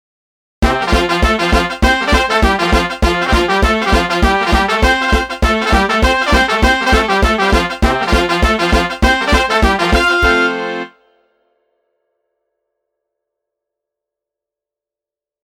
最初はゆっくりとしたノーマルものから、大部分の人ができるようになったときのレベルを上げてテンポを上げた音源も掲載。
200 Kintaro_temp200
Kintaro_temp200.mp3